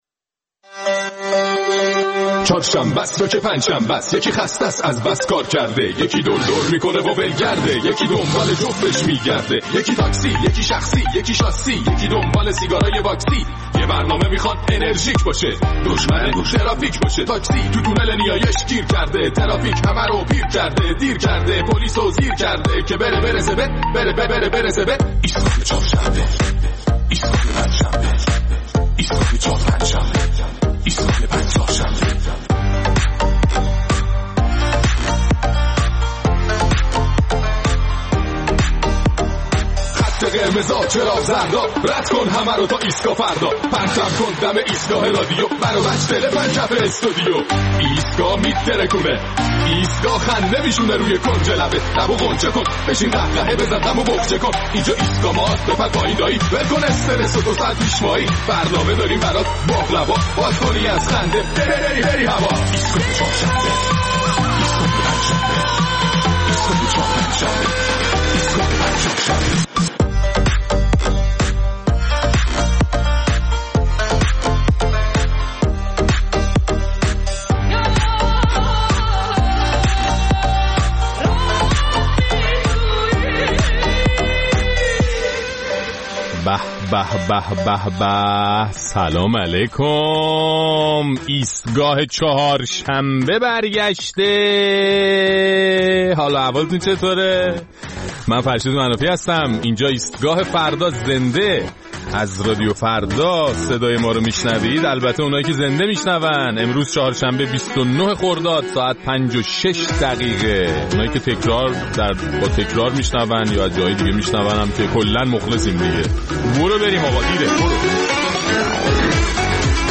در این برنامه با توجه به اظهارنظر رئیس شورای شهر تهران در مخالفت با زیست شبانه، نظرات و تجربیات شنوندگان‌مان را درباره زندگی شبانه و حواشی آن می‌شنویم.